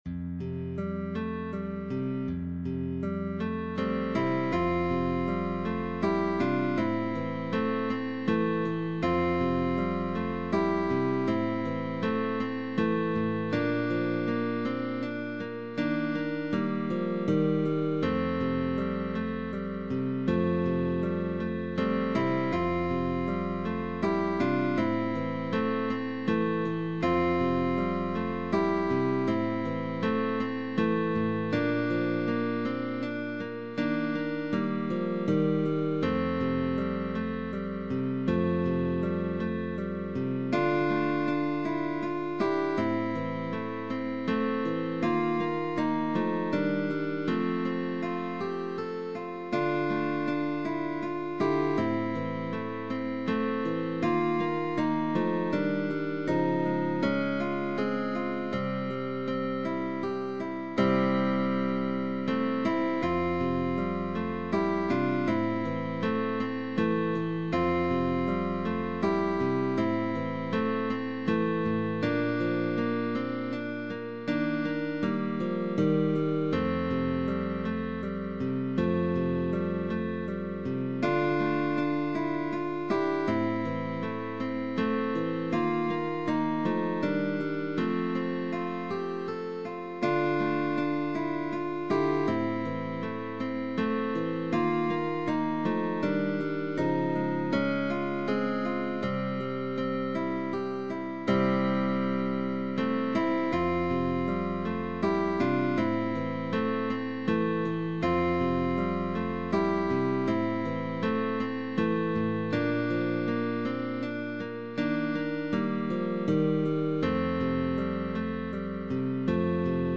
GUITAR TRIO
Pop Songs